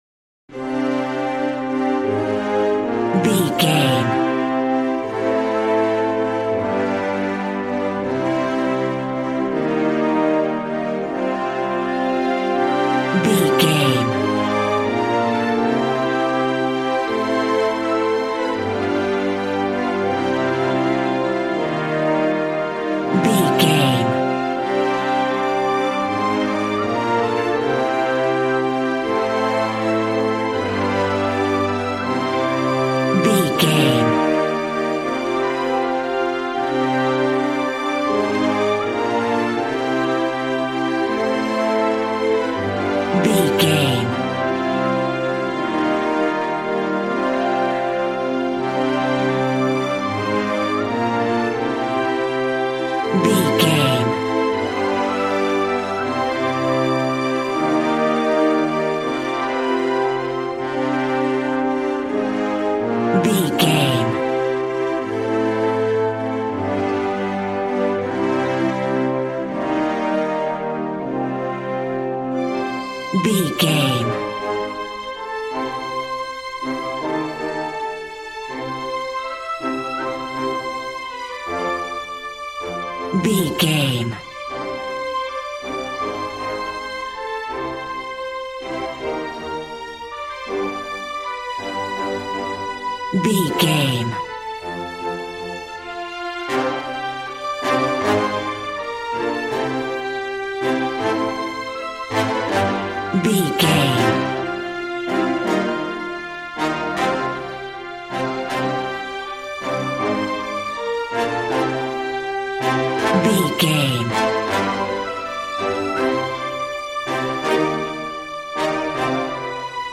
Regal and romantic, a classy piece of classical music.
Ionian/Major
regal
cello
double bass